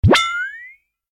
LB_evidence_hit.ogg